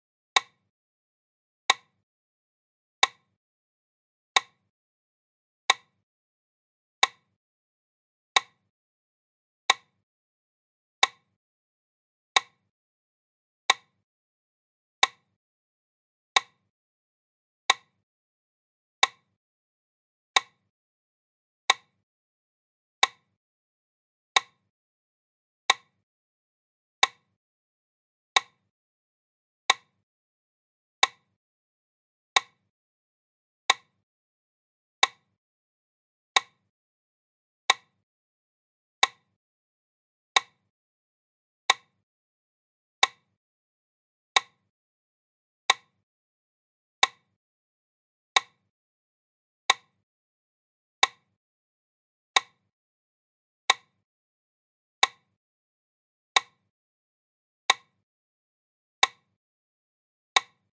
Звуки метронома
45 ударов за минуту